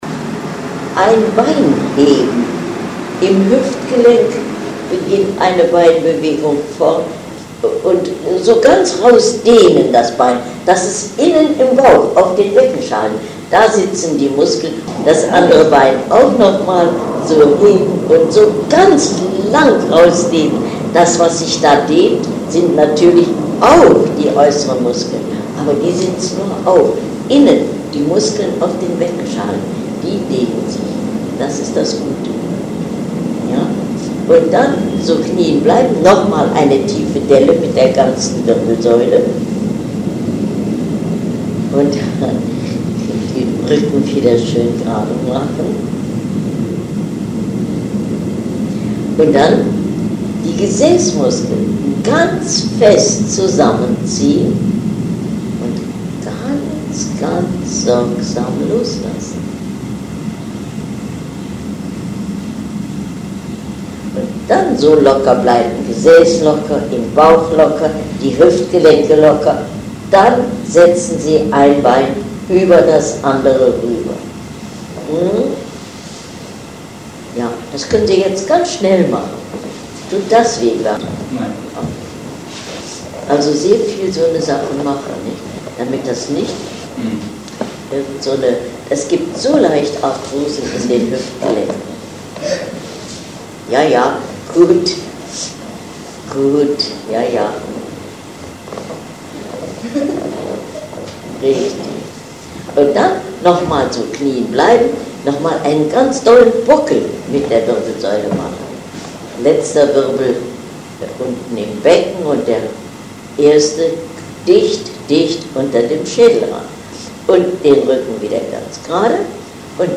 Ausschnitt aus einer Übungsstunde bei
Ihre außerordentlich kräftige und wohlklingende Stimme ließ spüren, dass sie im Einklang mit dem natürlichen Atemrhythmus war.